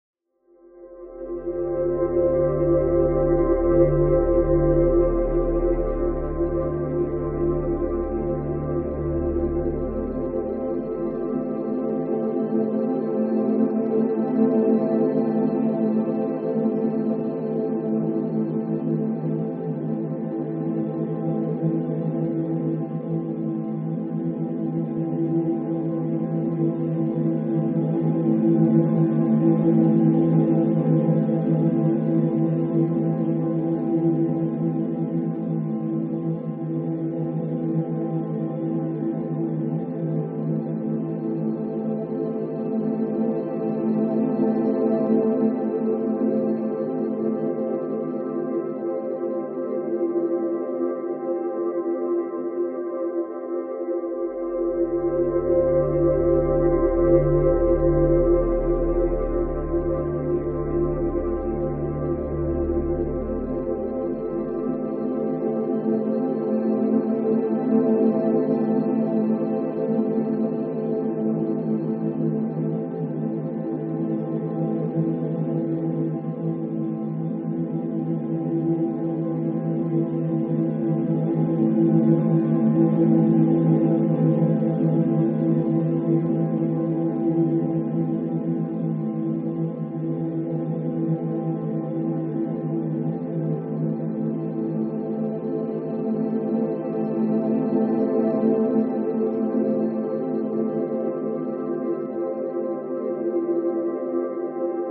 drone_compressed.mp3